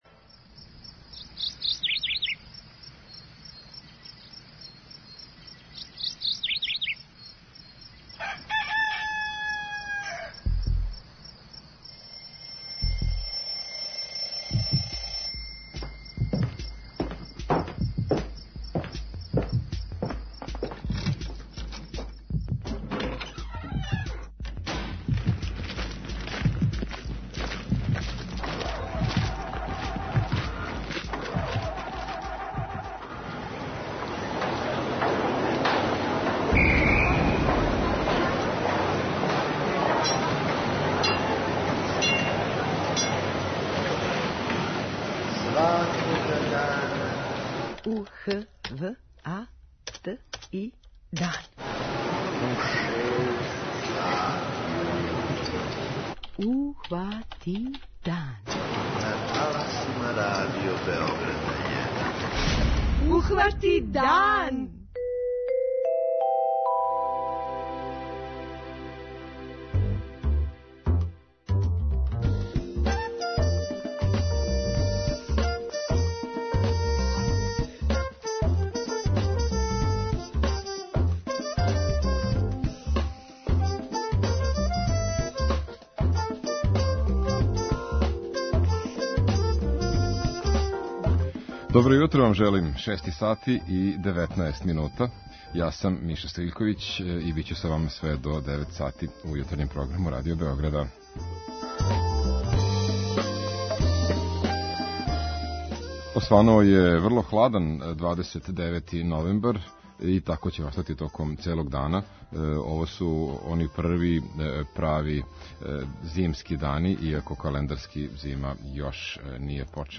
преузми : 29.21 MB Ухвати дан Autor: Група аутора Јутарњи програм Радио Београда 1!